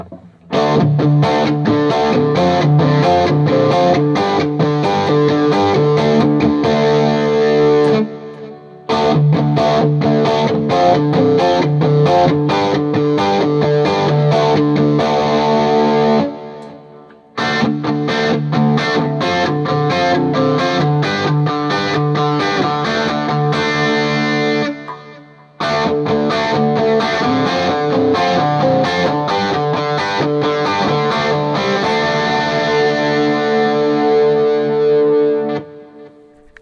Each recording goes though all of the pickup selections in the order: neck, both (in phase), both (out of phase), bridge.
BL D-Shaped Chords
I recorded the difference with the second set of recordings with the pickups farther from the strings.